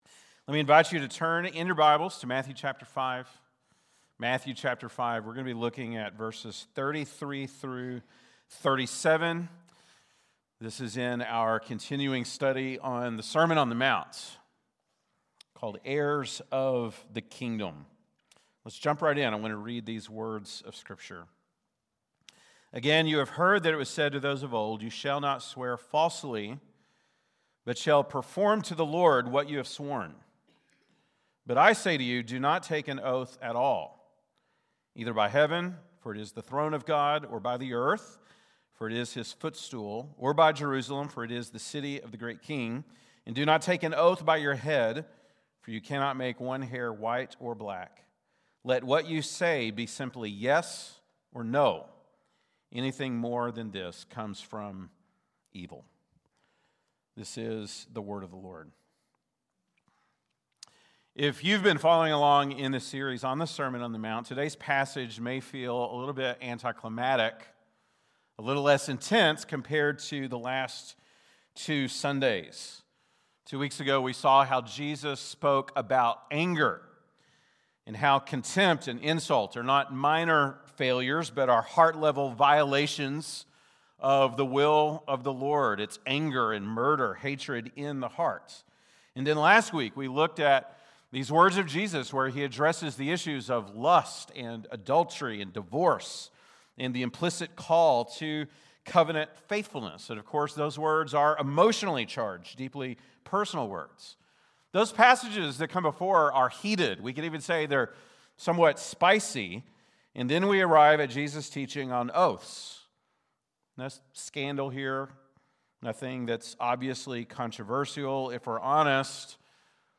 February 1, 2026 (Sunday Morning)